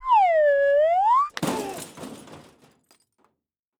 cartoon
Human Burping 1